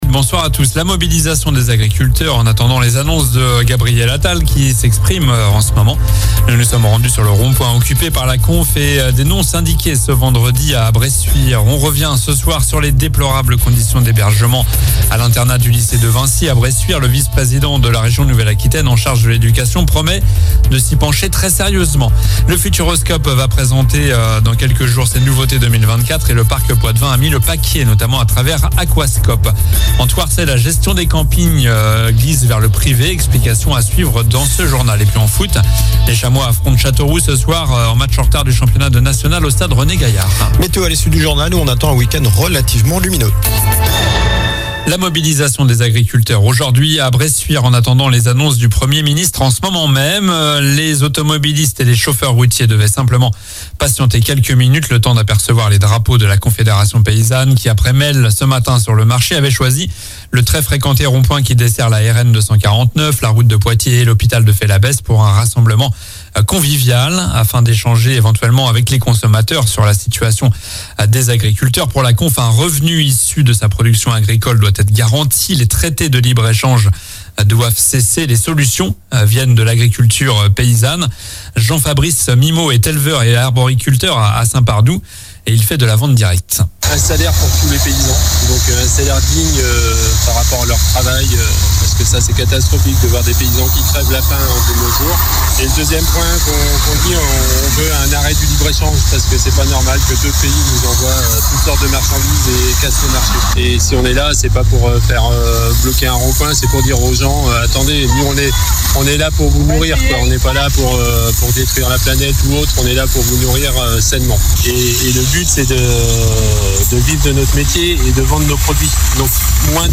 Journal du vendredi 26 janvier (soir)